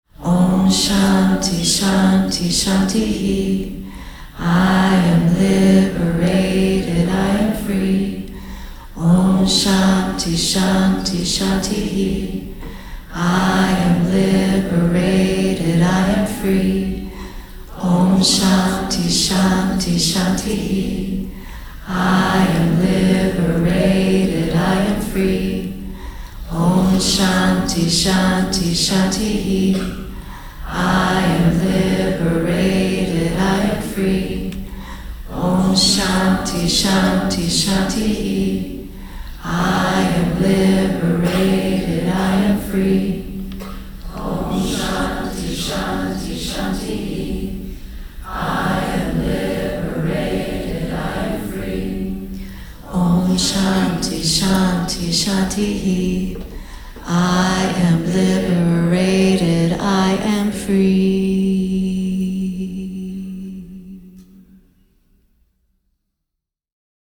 Recorded over the course of the March 2017 "Feel Good Now" 5-day Soul Convergence